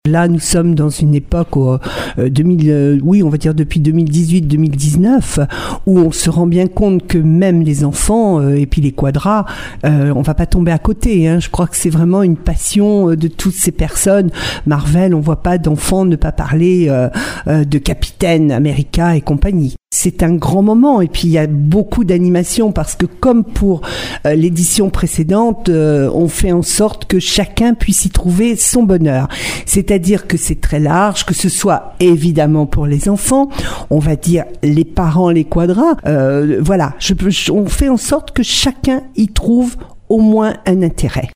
Cette 2e édition va rendre hommage à la pop culture américaine et aux super-héros Marvel, avec notamment la venue de Captain America. Un univers dont raffolent petits et grands comme le souligne Dominique Bergerot, adjointe au maire en charge de la communication :